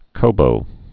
(kô)